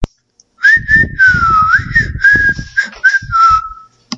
口哨声
标签： 快乐 吹口哨 吹口哨
声道立体声